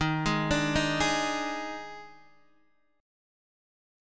D#M7sus4 chord